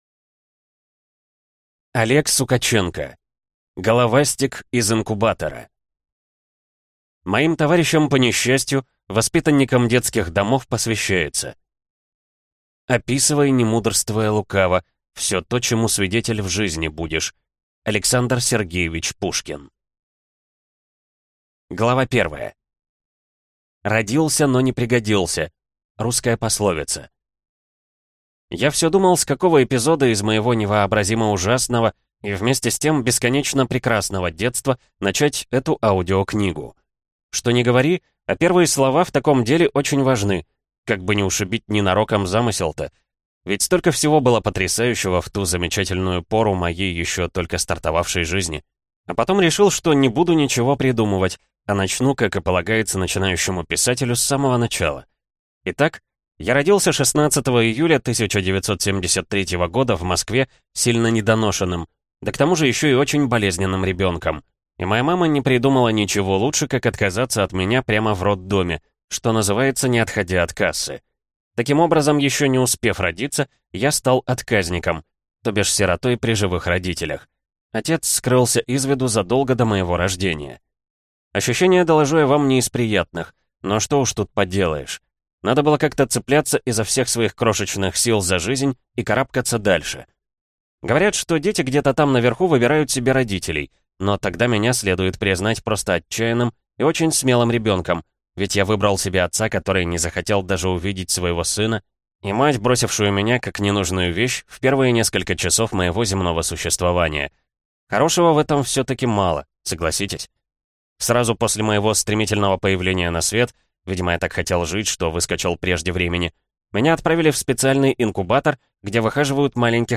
Аудиокнига Головастик из инкубатора | Библиотека аудиокниг
Прослушать и бесплатно скачать фрагмент аудиокниги